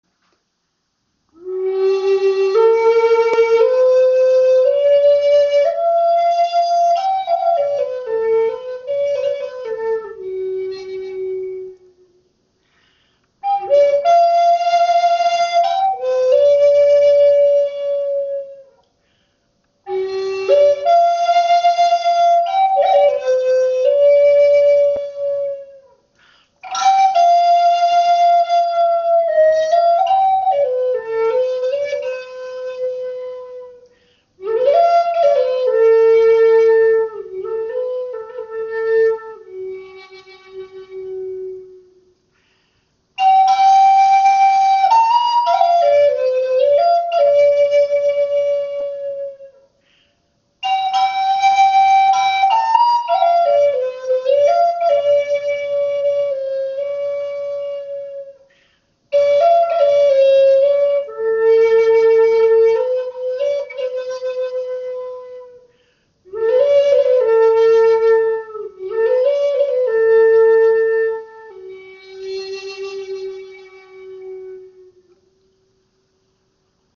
Klangbeispiel
Diese Gebetsflöte in G ist auf 432 Hz gestimmt. Sie wurde aus dem Holz eines Nussbaumes geschaffen und abschliessend geölt, so dass sie ein seidenglänzendes Finish hat.